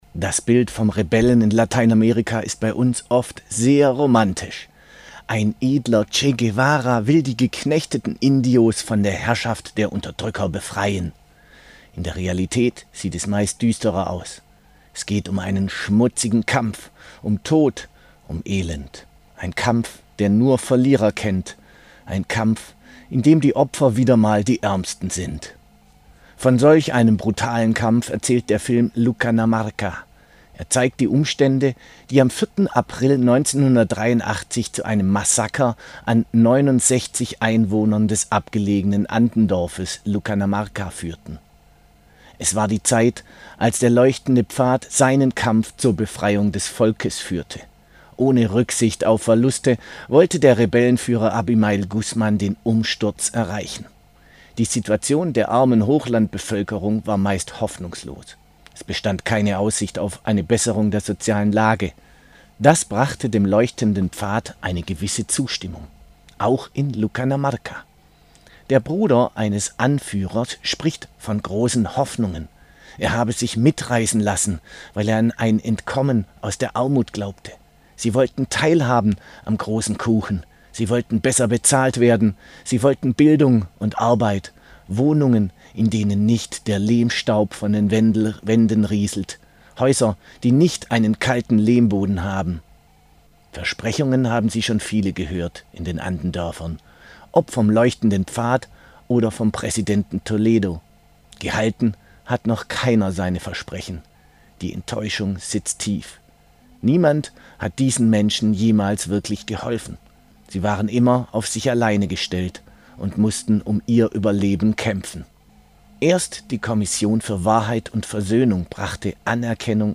Kultur